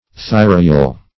Search Result for " thyrohyal" : The Collaborative International Dictionary of English v.0.48: Thyrohyal \Thy`ro*hy"al\, n. (Anat.)
thyrohyal.mp3